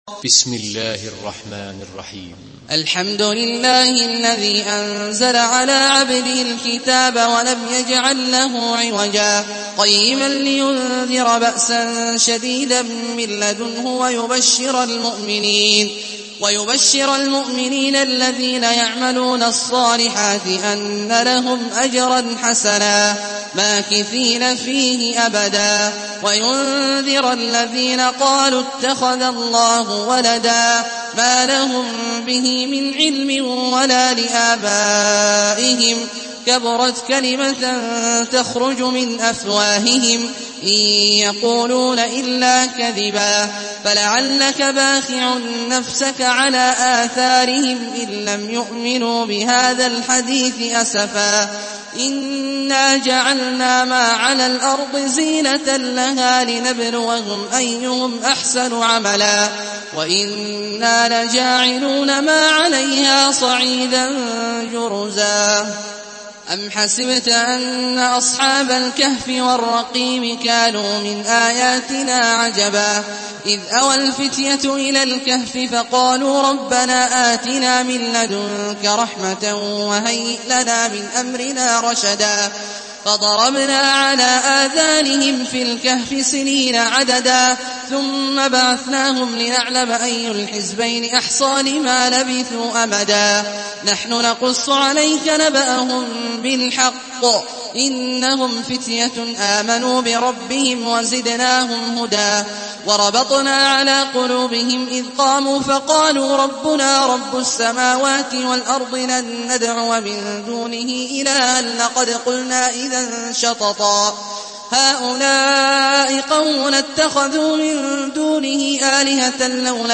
سورة الكهف MP3 بصوت عبد الله الجهني برواية حفص
مرتل